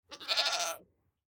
Minecraft Version Minecraft Version snapshot Latest Release | Latest Snapshot snapshot / assets / minecraft / sounds / mob / goat / idle4.ogg Compare With Compare With Latest Release | Latest Snapshot